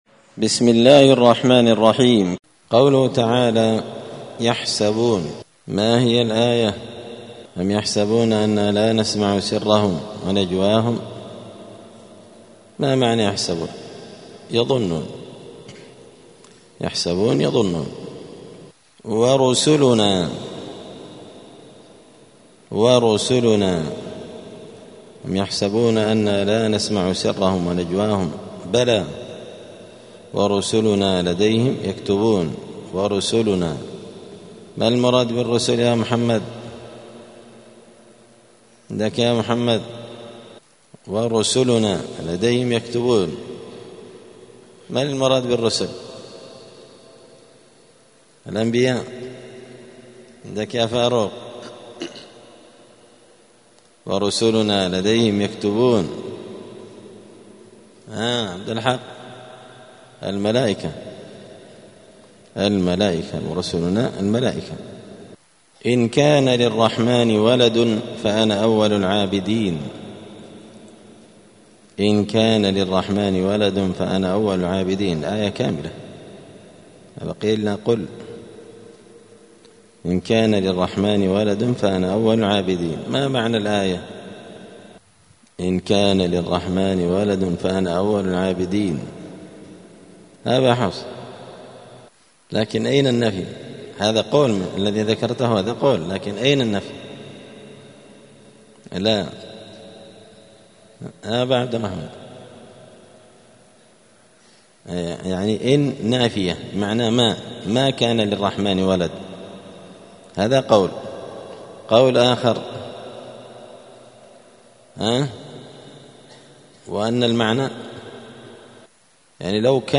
الأربعاء 22 رجب 1446 هــــ | الدروس، دروس القران وعلومة، زبدة الأقوال في غريب كلام المتعال | شارك بتعليقك | 34 المشاهدات